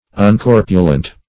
uncorpulent.mp3